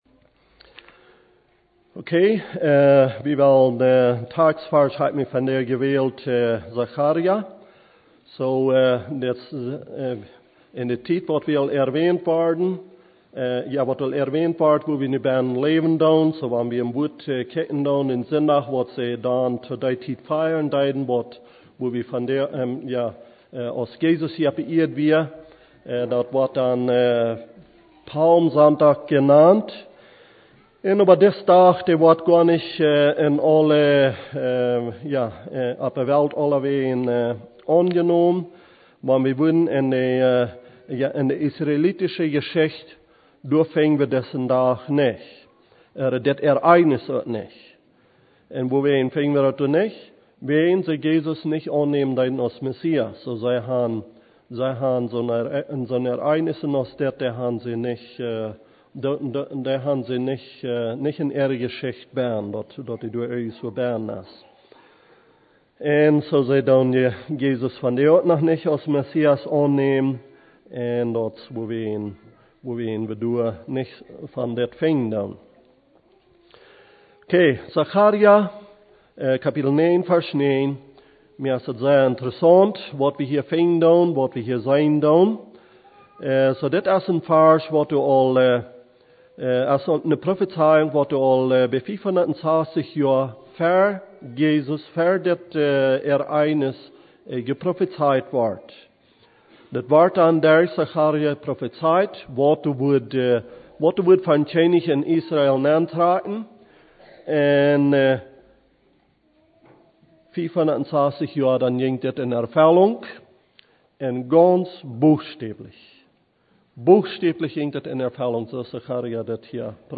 Pred.